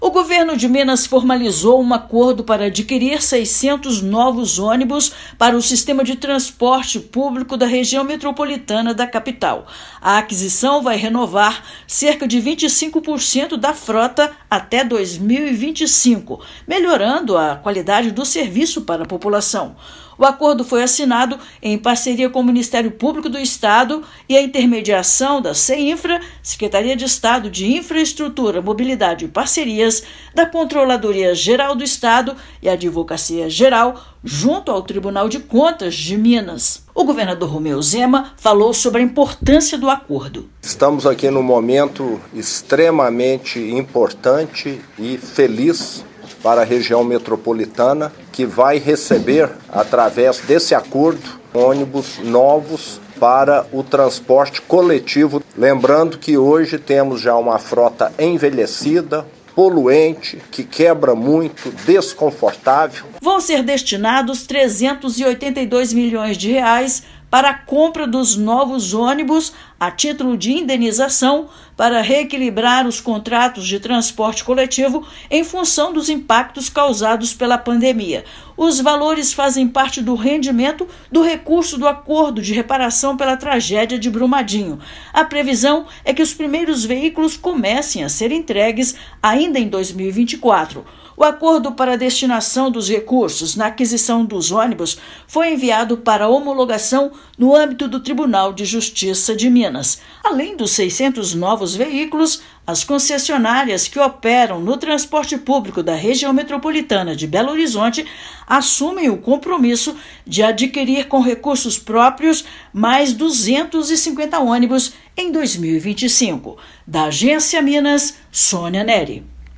Serão destinados R$ 382 milhões para aquisição de veículos pelas concessionárias, melhorando a qualidade de transporte diário para mais de 500 mil pessoas. Ouça matéria de rádio.